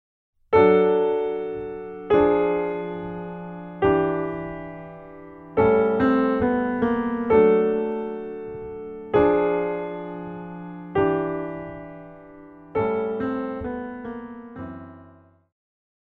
古典,流行
小提琴
钢琴
演奏曲
世界音乐
仅伴奏
没有主奏
没有节拍器